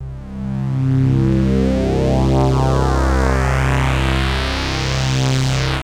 Index of /90_sSampleCDs/Zero-G - Total Drum Bass/Instruments - 1/track12 (Pads)